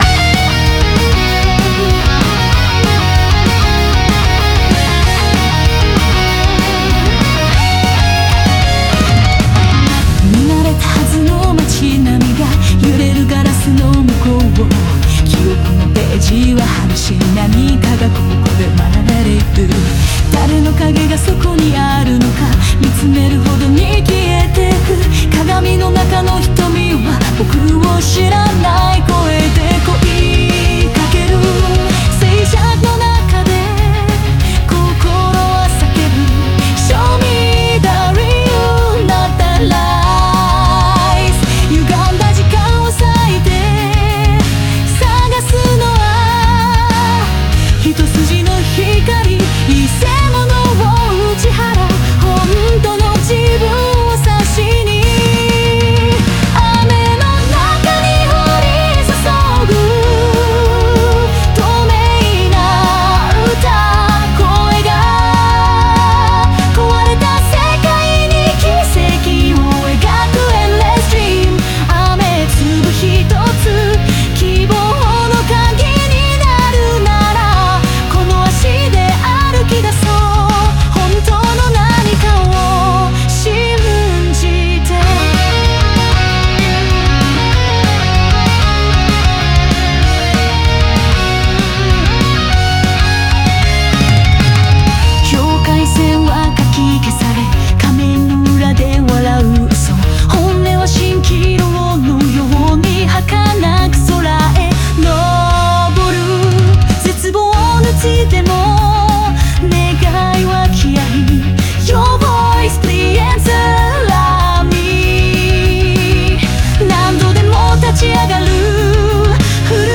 Metal Ballad